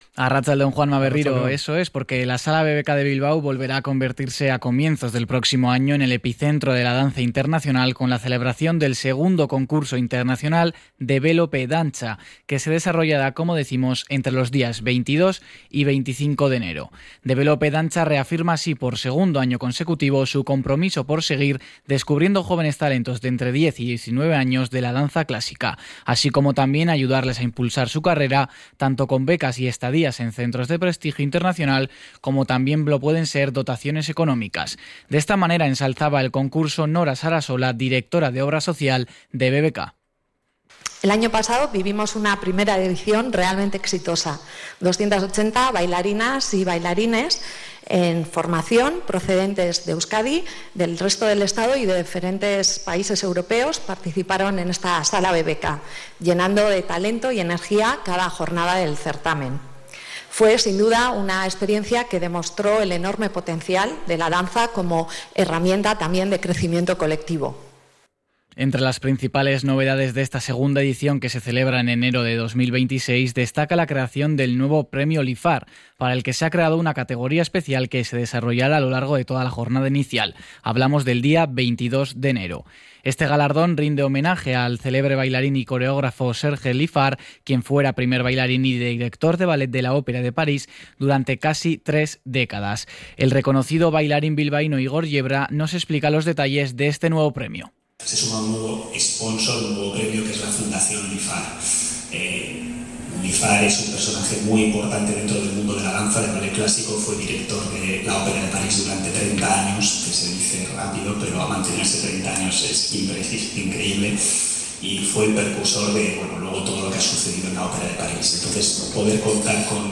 Igor Yebra anuncia las principales novedades de la segunda edición